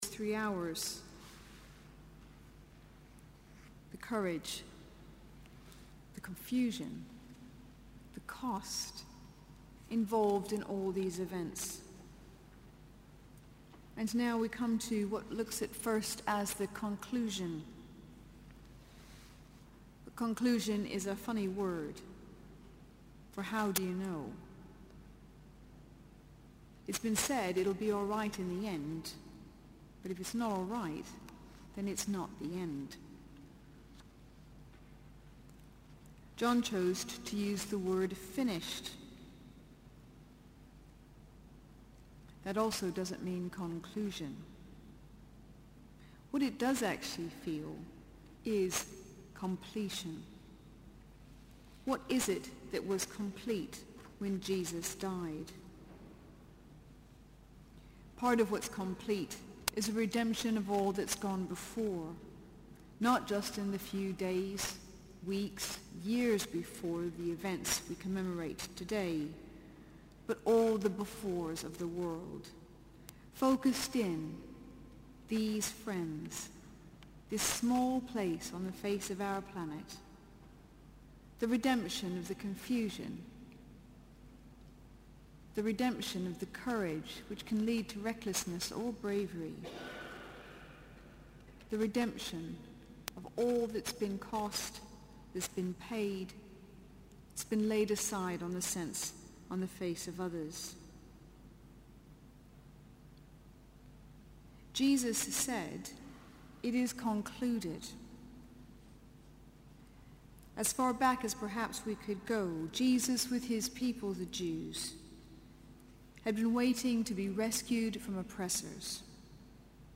Sermon: Good Friday 2014 Eucharist
Friday 18th April 2014 Service: Good Friday Eucharist Listen